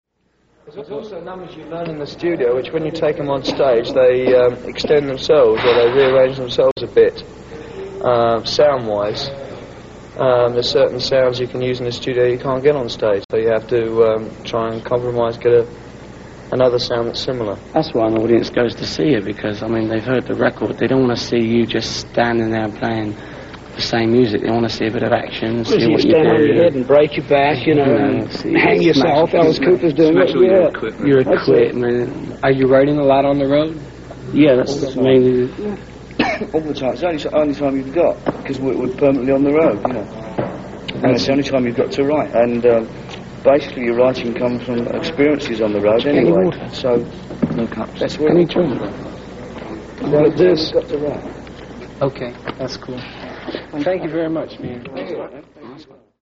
Venue: Dick Clark Studios / Minneapolis, MN
Source:  VHS Audio Rip
Interview
Audio source taken from a VHS copy of the Bijou Theater TV pilot film that never aired.
BijouInterview.mp3